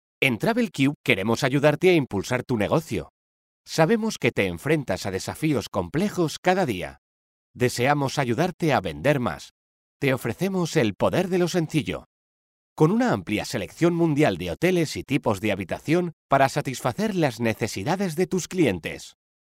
I have mi own home studio in which I do the work in the shortest possible time.
My vocal color is professional, youth, warm, persuasive and friendly.
Sprechprobe: Werbung (Muttersprache):
I am a professional neutral Spanish voice artist.